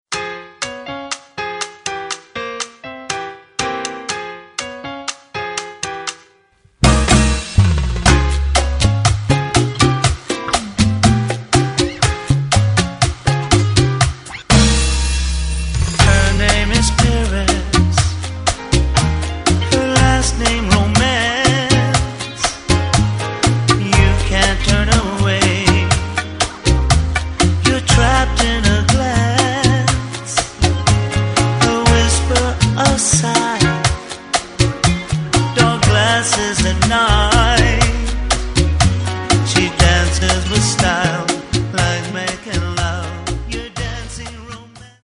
究極のチカーノ・ソウル・コンピレーション！